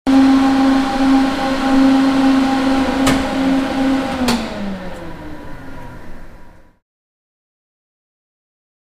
Engine; Shock Tunnel Hum And Slow Down To Stop